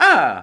Super Mario Ahhh Sound Effect Free Download